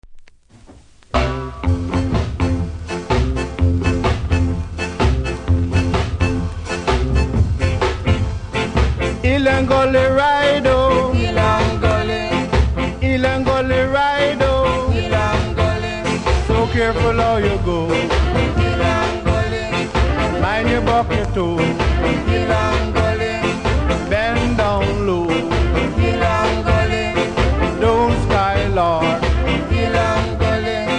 多少キズありますが音にはそれほど影響せず良好です。